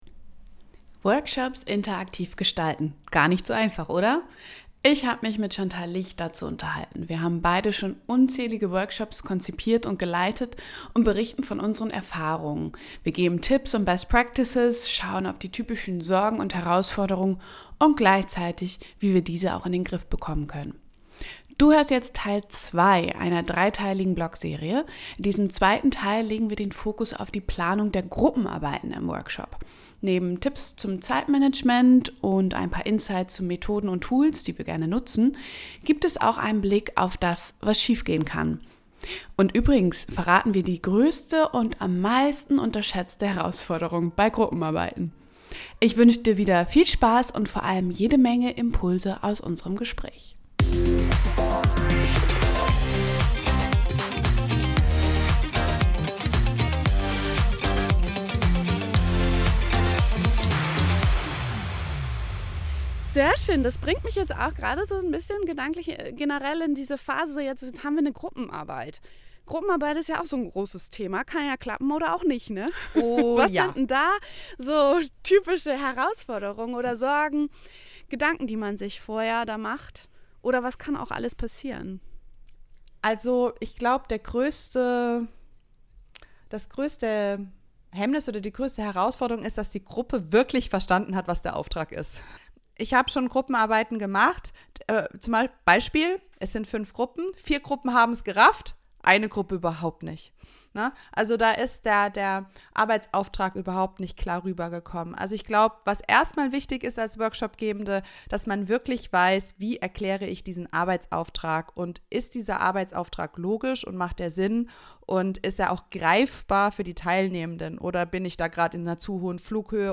Wenn du dich schon immer gefragt hast, wie du Gruppenarbeiten effektiv organisieren kannst, um maximale Teilnahme und Zusammenarbeit zu gewährleisten, dann ist dieses Gespräch genau das Richtige für dich! Wir teilen nicht nur bewährte Tipps und Strategien, sondern bringen auch unsere langjährige Erfahrung als Workshop-Facilitatorinnen und Lernexpertinnen ein.